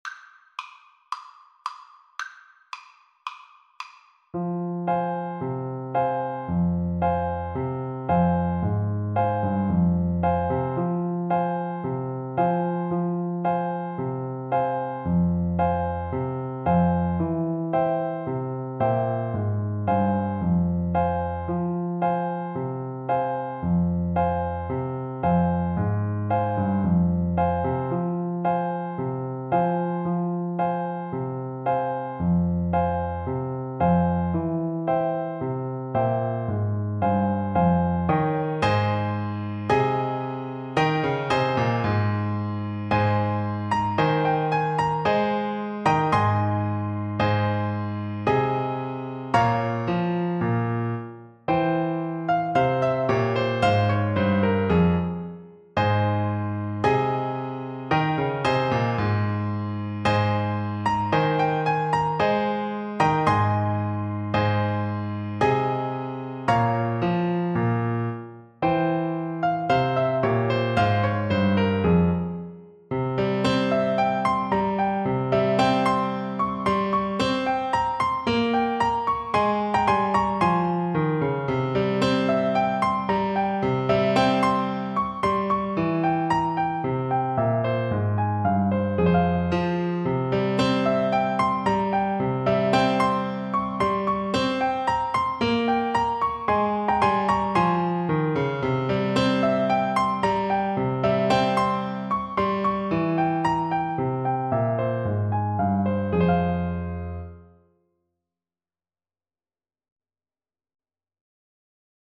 Play (or use space bar on your keyboard) Pause Music Playalong - Piano Accompaniment Playalong Band Accompaniment not yet available transpose reset tempo print settings full screen
Clarinet
4/4 (View more 4/4 Music)
F minor (Sounding Pitch) G minor (Clarinet in Bb) (View more F minor Music for Clarinet )
Allegro = 112 (View more music marked Allegro)
Greek